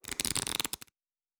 Cards Shuffle 1_10.wav